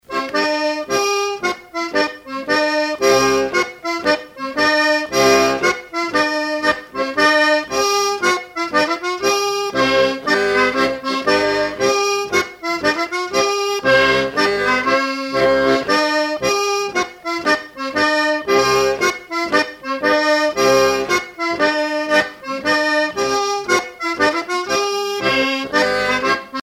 danse : ronde
Pièce musicale éditée